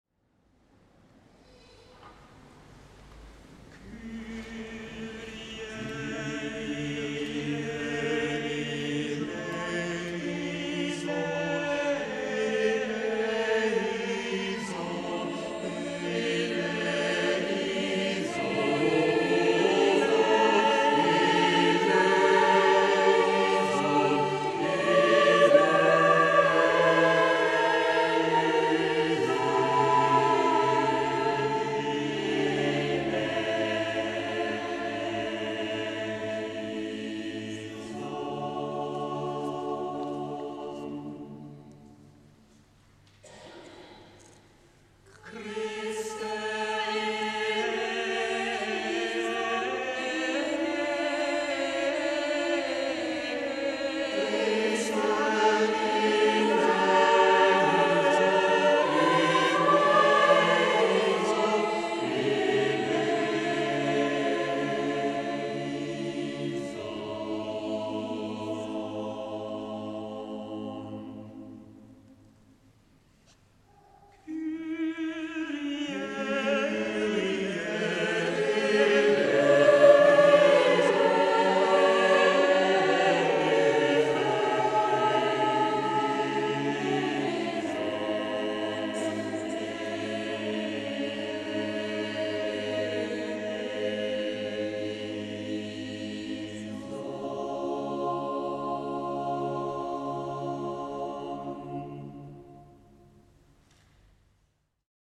Musikalischer Abschluss der dritten Oktav 2013
Kinder- und Jugendchor
Projektchor